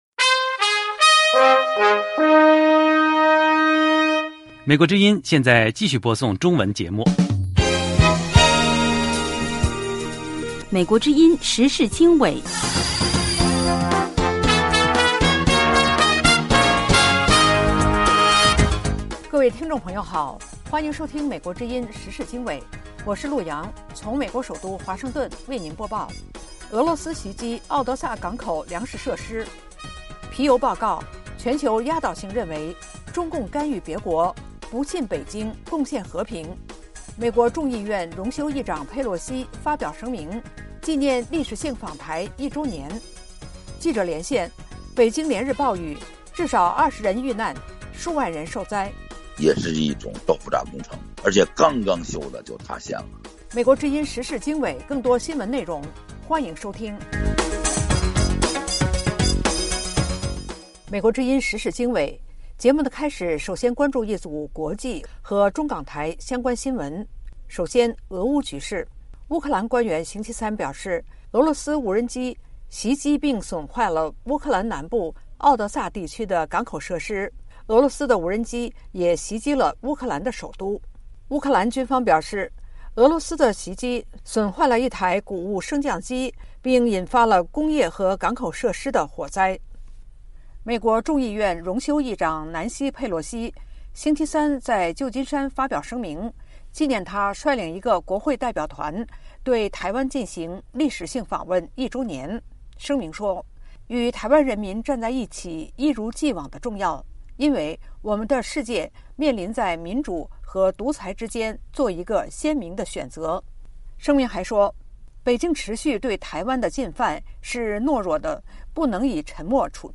1/俄罗斯袭击敖德萨港口粮食设施 ；2/皮尤民调：全球压倒性认为中共干预别国，不信北京贡献和平；3/佩洛西荣休议长发声明纪念历史性访台周年； 4/记者连线：北京连日暴雨，至少数十人人遇难数万人受灾